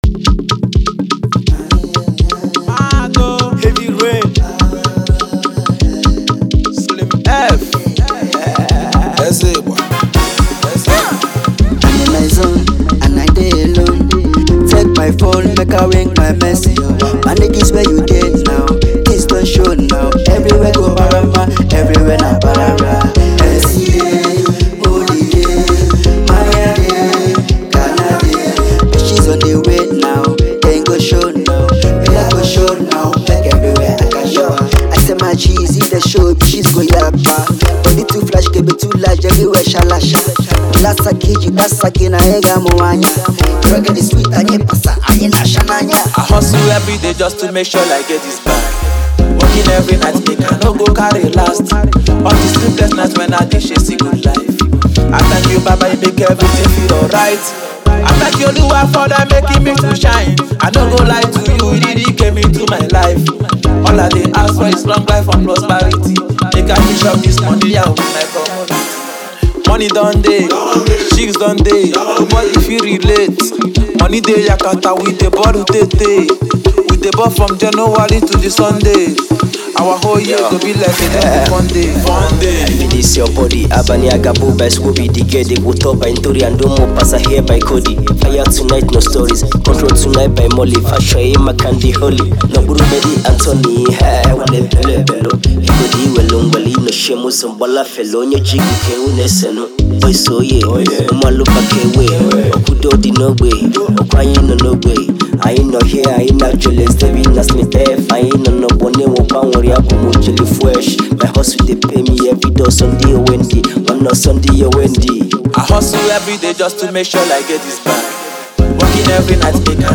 • Genre: Afropop / Nigerian Music